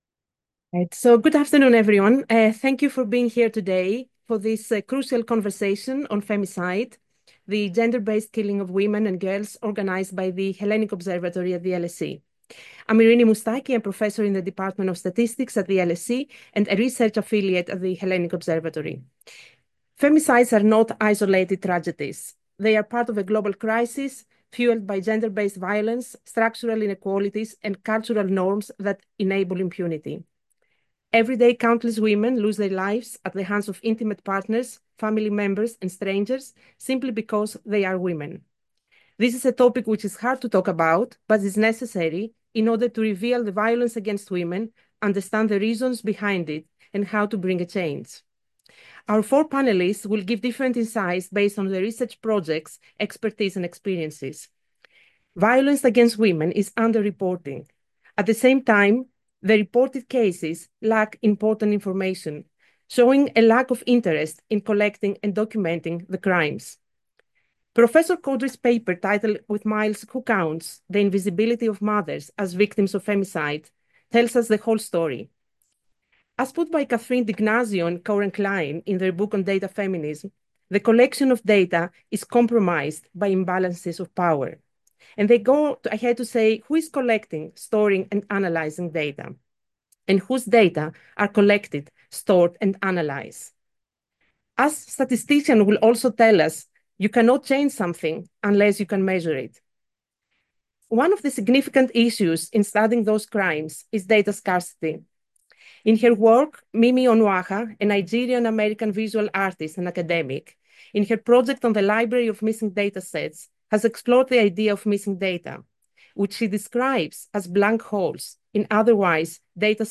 Bringing together journalists, academics, and policymakers, the panel aims to reflect on how the Greek media contribute to the legitimation, or contestation, of hierarchical valuations of women’s lives and to foster a vital conversation on gendered representations, on accountability, and systemic change.